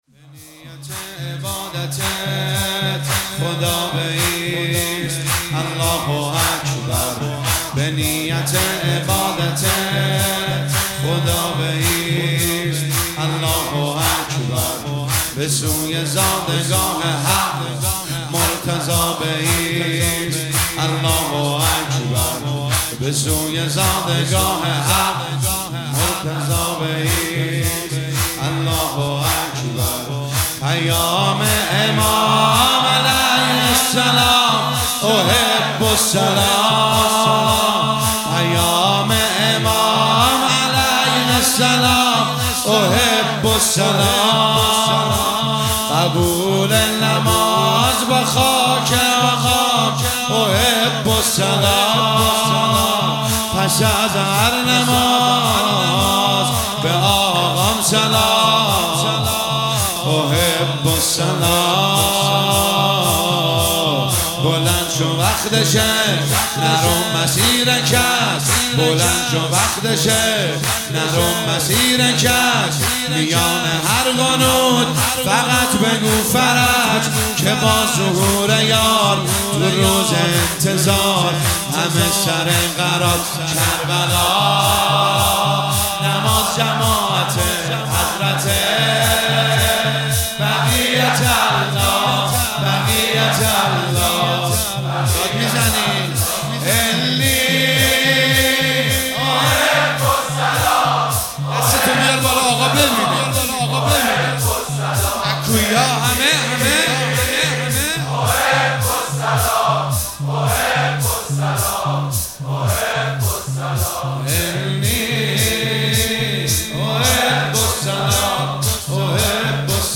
مداحی پیش زمینه شب هفتم محرم 1445
هیئت خادم الرضا قم